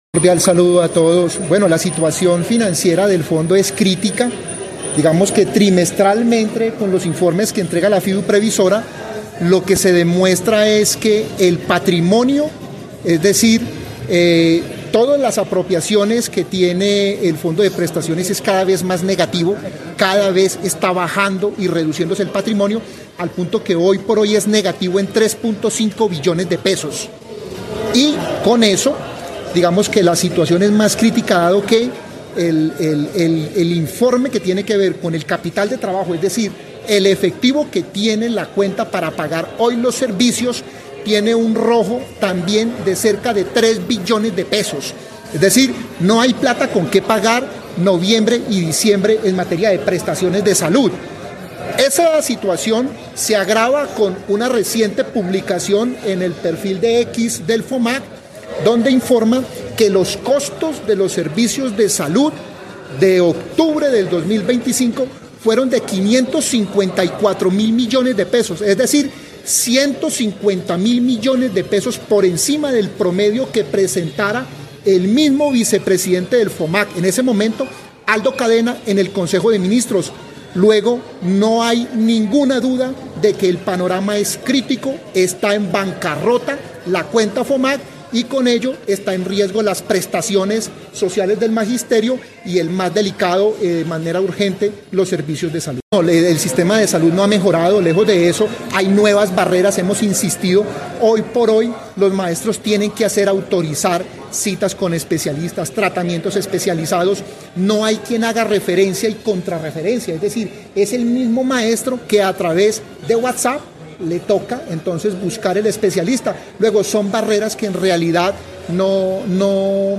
En Caracol Radio Armenia hablamos con